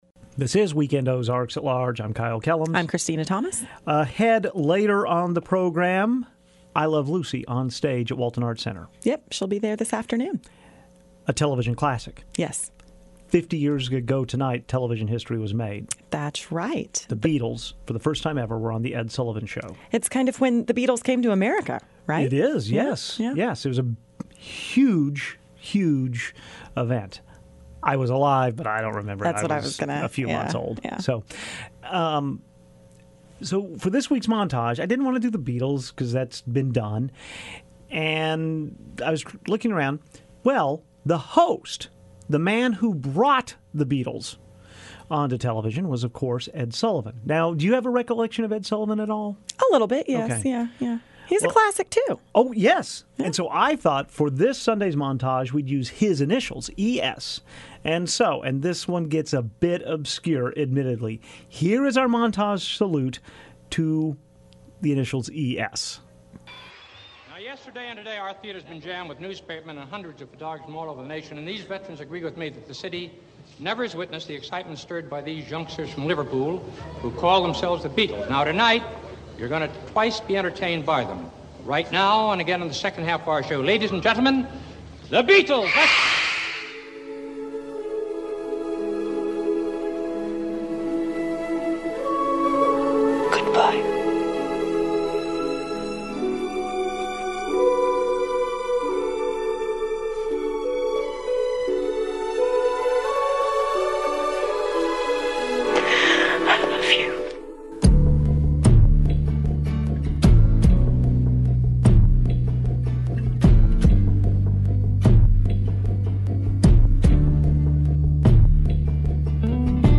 On some we ESchew the usual rules of capitalization to highlight our special letters. 1) Ed Sullivan introduces a famous band 50 years ago today. 2) Edward Scissorhands (Johnny Depp) says goodbye. 3) Ed Sheeran sings Kiss Me. 4) Ebenezer Scrooge is visited by a ghost. This ES is played by Albert Finney. 5) The theme to ESpn's Sports Center. 6) Emma Stone sees Ryan Gosling in Crazy, Stupid Love. 7) Esperanza Spalding performs Precious. 8) Kreskin, famed "mentalist" during the height of the ESp craze on TV. (OK, we're stretching it...) 9) Elizabeth Shue meets the Karate Kid for a date. 10) Edward Sharpe and the Magnetic Zeros (soon to be seen at Wakarusa) sing Home.